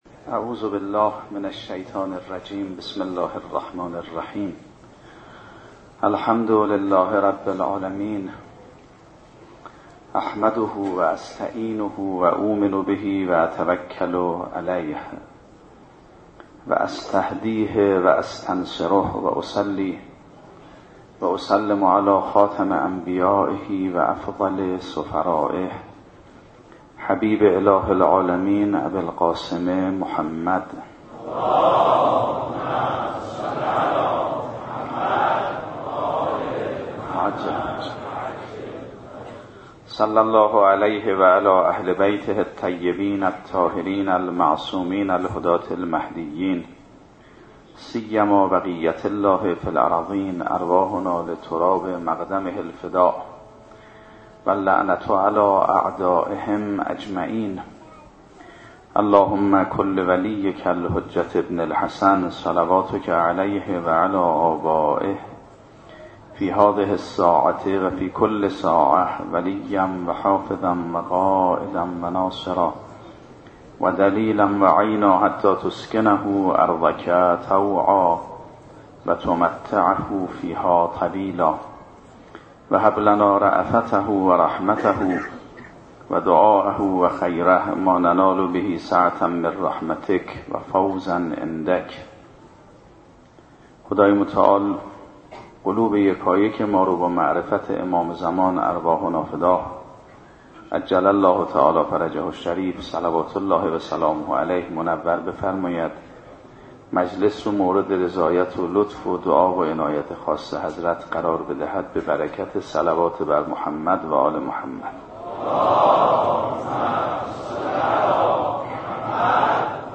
گروه معارف - رجانیوز: آنچه پیش رو دارید نهمین جلسه سخنرانی آیت الله سید محمد مهدی میرباقری؛ رئیس فرهنگستان علوم اسلامی قم است که در دهه اول محرم الحرام سال 95 در هیأت ثارالله قم (مدرسه فیضیه) برگزار شده است. آیت الله میرباقری در ادامه جلسه قبل بیان داشت برای همراهی همه جانبه با ولی خدا شروطی لازم است.
سخنرانی آیت الله میرباقری- هیئت ثارالله (9).mp3